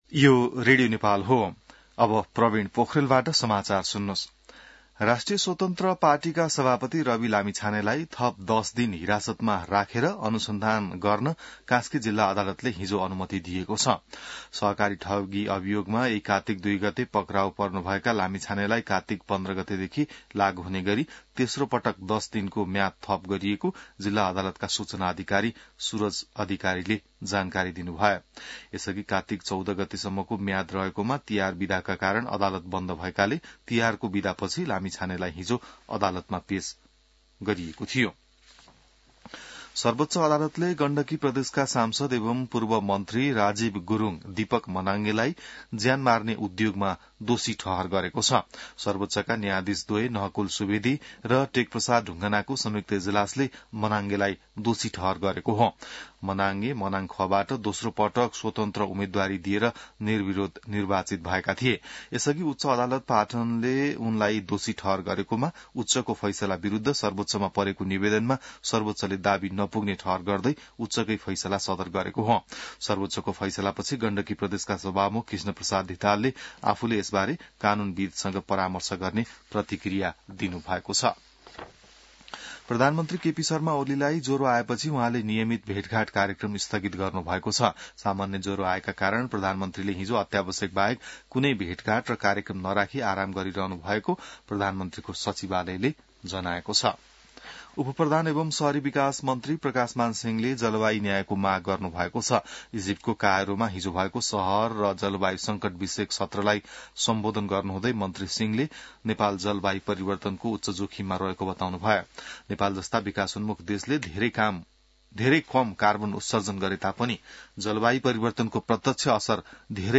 बिहान ६ बजेको नेपाली समाचार : २२ कार्तिक , २०८१